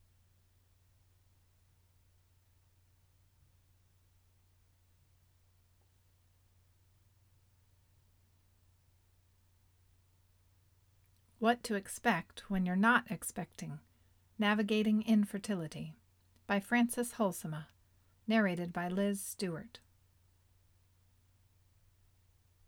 I am using a Samson C01Upro microphone in Audacity to record an audio book.
I don’t know if I accidently changed an input setting somewhere, but now it is recording at a super low level and won’t pass the ACX Check analysis!
Recording Channels: 1 mono